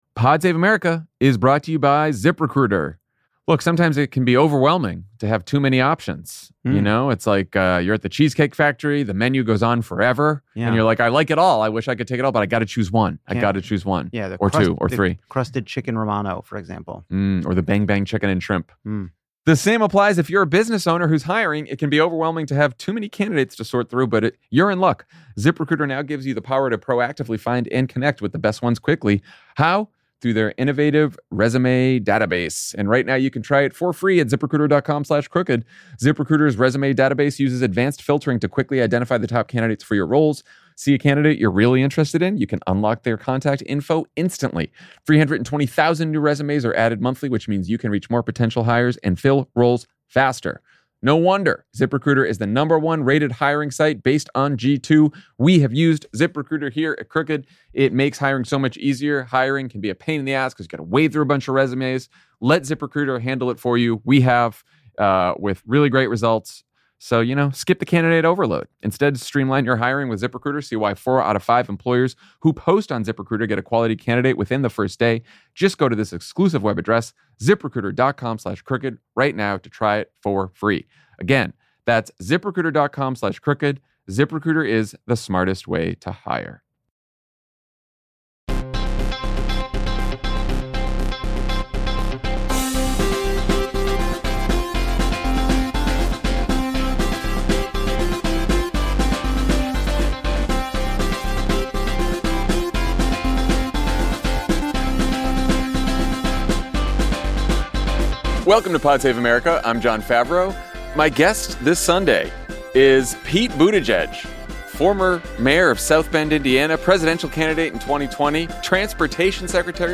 Pete Buttigieg—former South Bend mayor, 2020 presidential candidate, and Transportation Secretary—sits down with Jon Favreau to discuss how much of the status quo Democrats should aim to restore (if any) if they win in 2026, what the party needs to change to effectively message around Trump’s broken promises, and what Pete thinks of JD Vance’s rapid ascent to power—and the values he’s abandoned along the way.